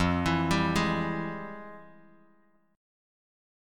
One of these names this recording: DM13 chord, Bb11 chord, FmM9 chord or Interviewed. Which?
FmM9 chord